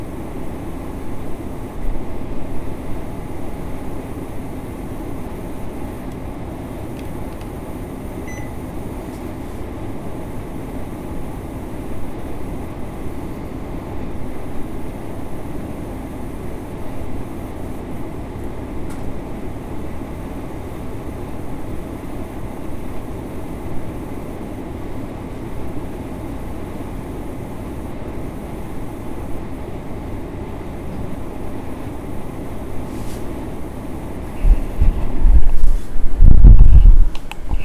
speaking-thinking-affecting-air-con-buzz.mp3